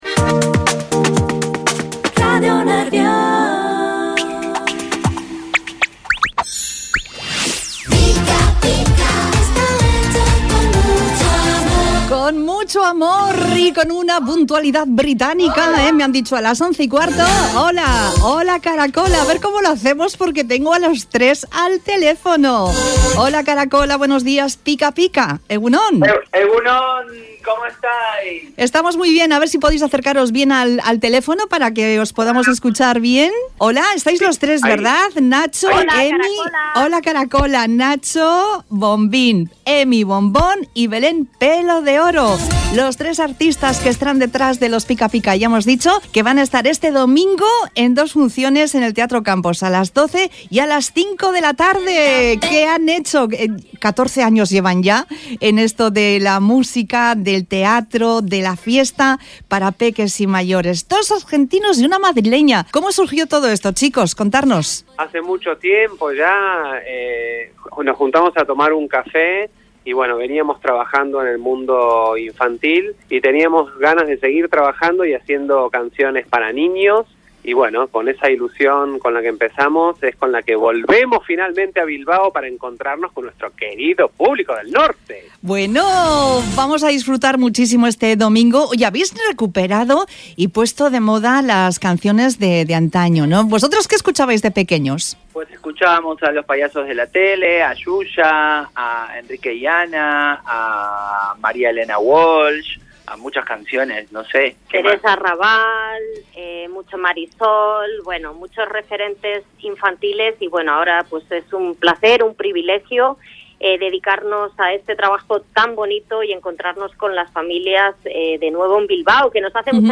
Entrevista-Pica-Pica-8-5-25.mp3